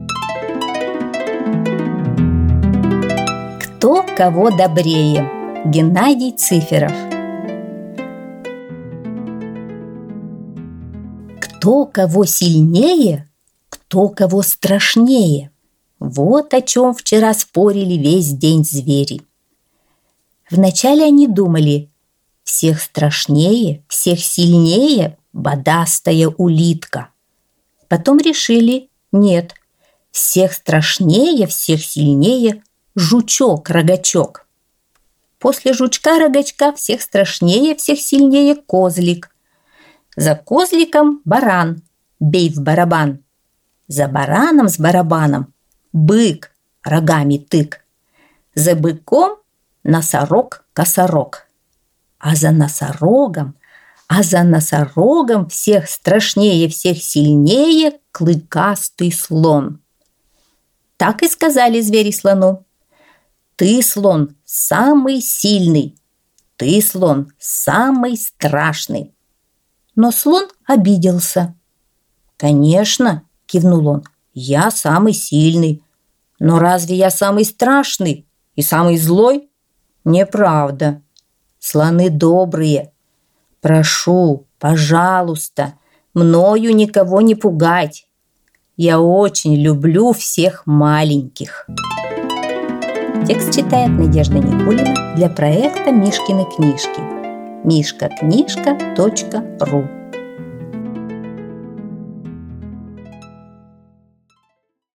Аудиосказка «Кто кого добрее»